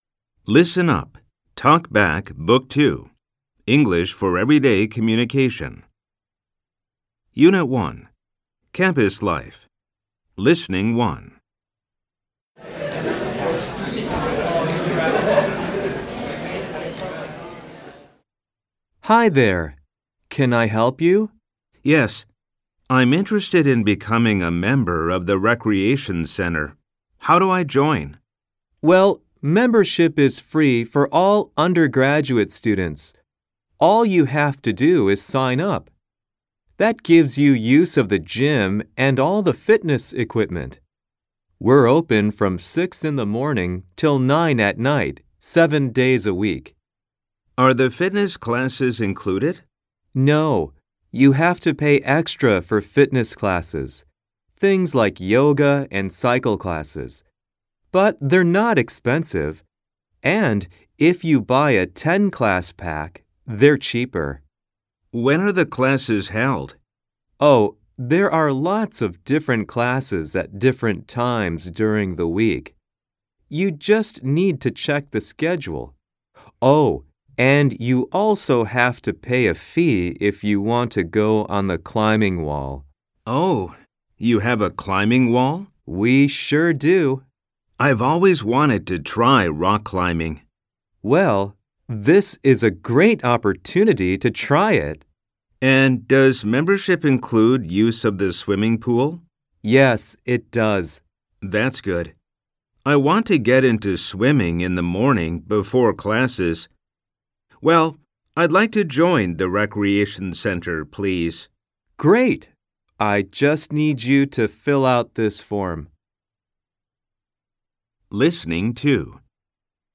ジャンル リスニング・スピーキング / 高校～大学用教材
吹き込み Amer E